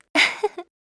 Isaiah-Vox-Laugh_kr.wav